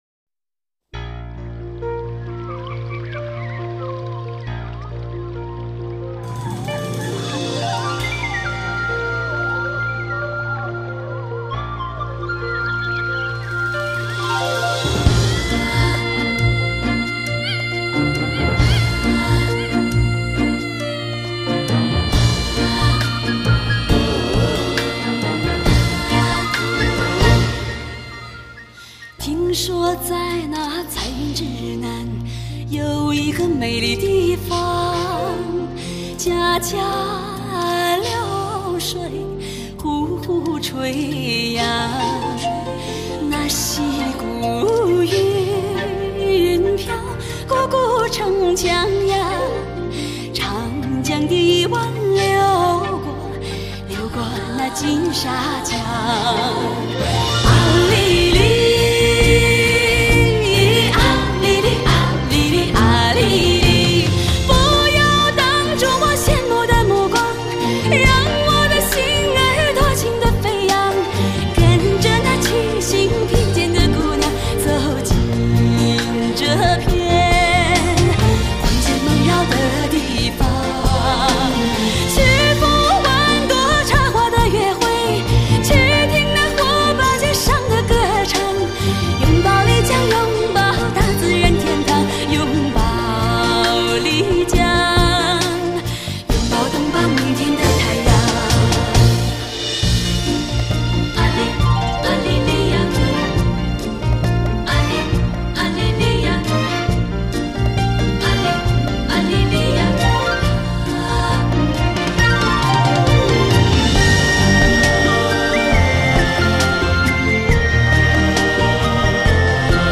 旋律欢快，节奏明亮